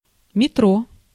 Ääntäminen
Synonyymit tube el (brittienglanti) underground Tube metropolitan (amerikanenglanti) El el ou EL underground ou underground railway (amerikanenglanti) subway underground railway Ääntäminen US UK : IPA : /ˈmɛtɹəʊ/